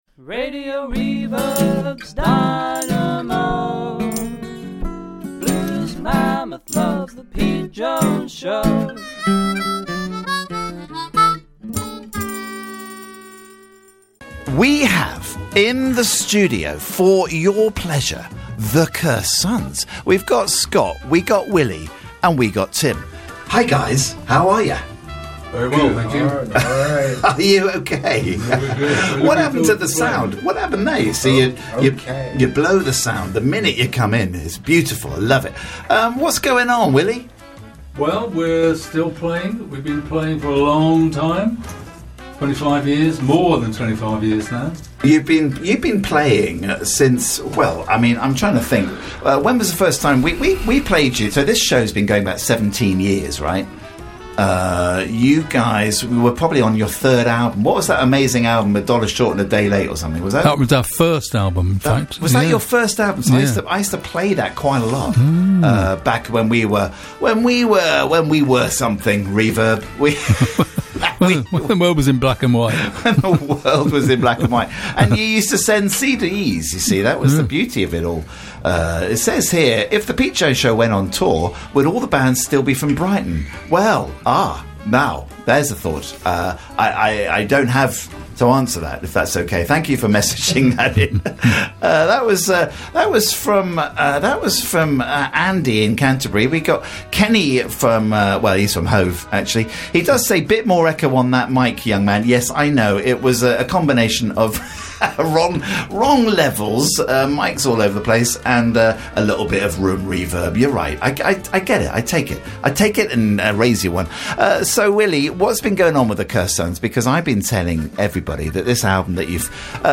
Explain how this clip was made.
Live session with The Curst Sons 25th November 2024